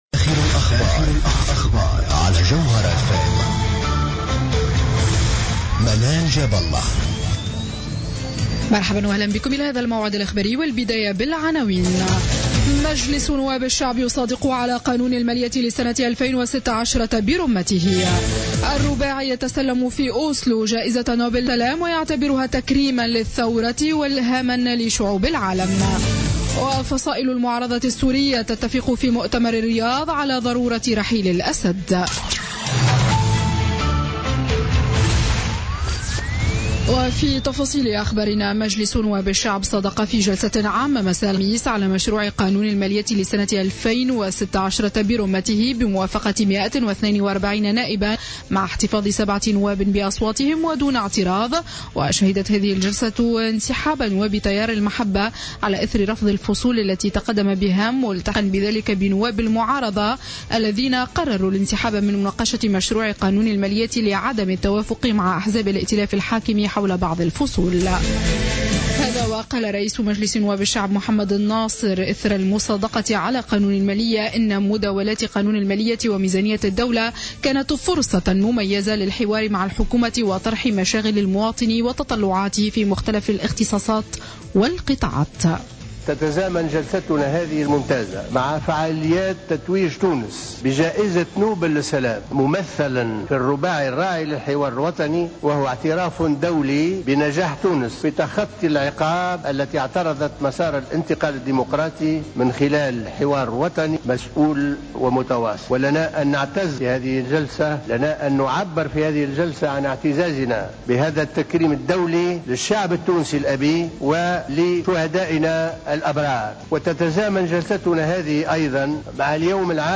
نشرة أخبار منتصف الليل ليوم الجمعة 11 ديسمبر 2015